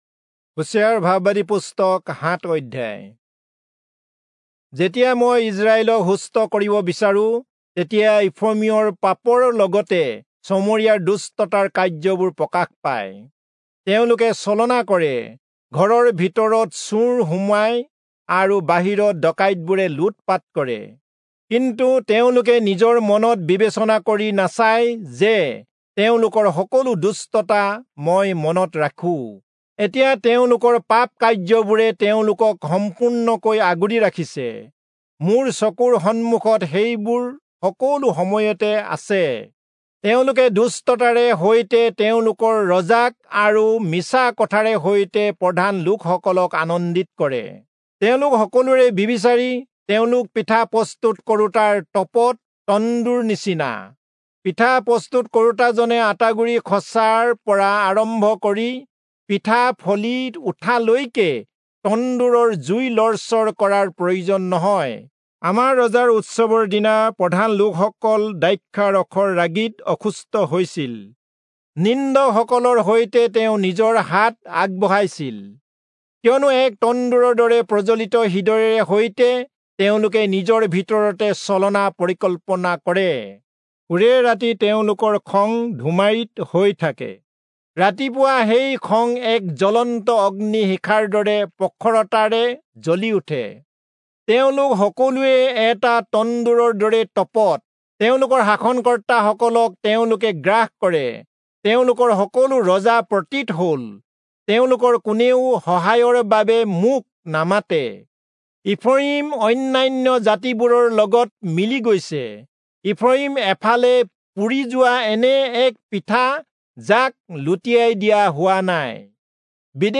Assamese Audio Bible - Hosea 8 in Net bible version